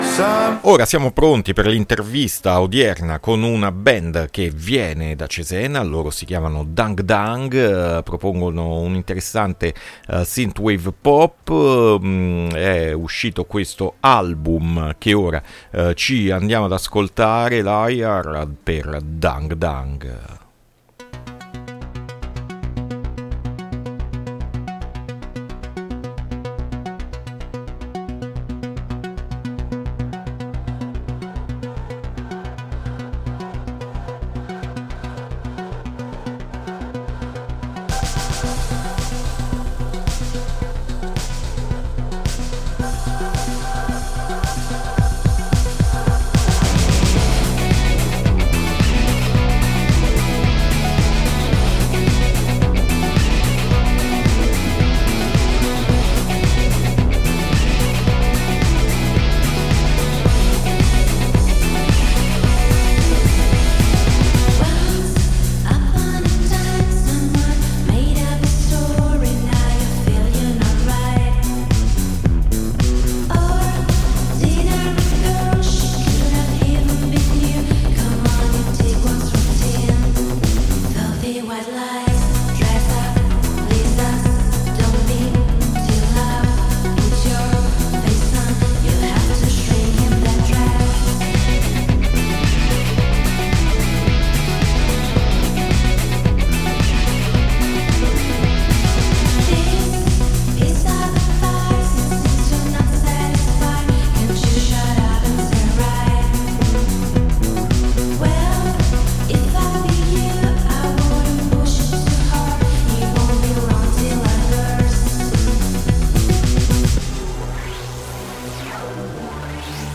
INTERVISTA DANG DANG A MERCOLEDI' MORNING 2-8-2023